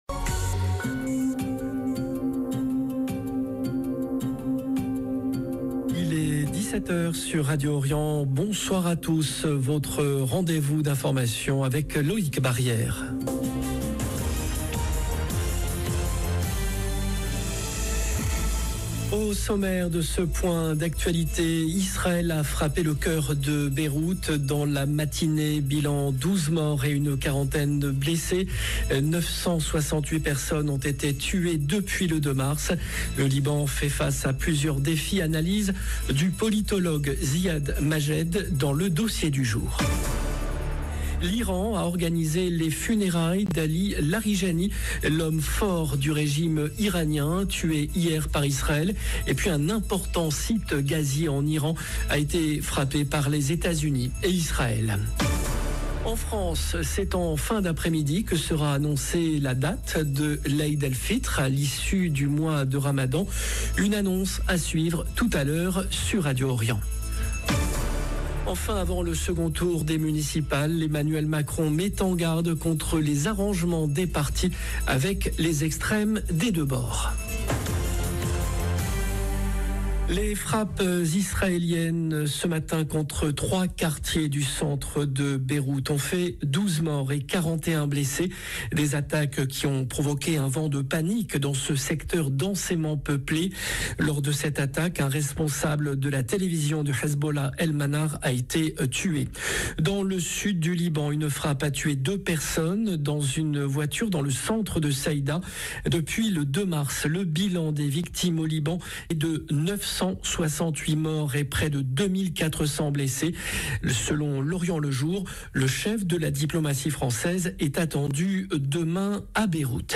Journal de 17H Au sommaire: Israël a frappé le cœur de Beyrouth dans la matinée.